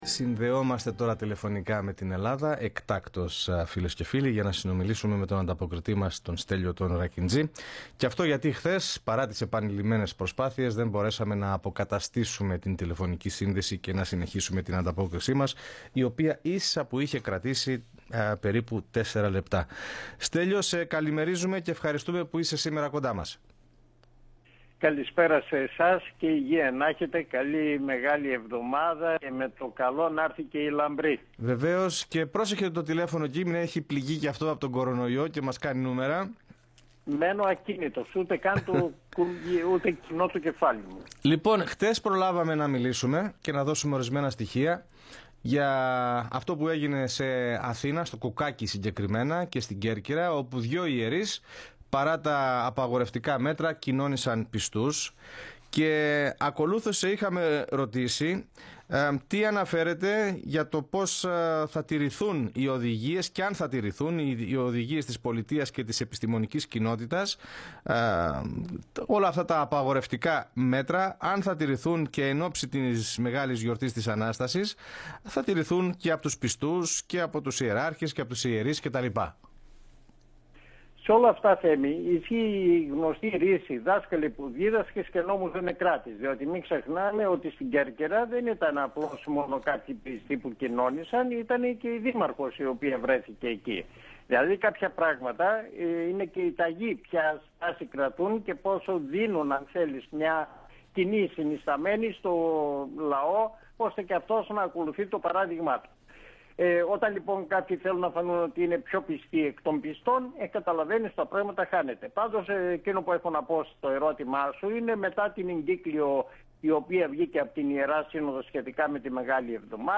report_from_greece_2.mp3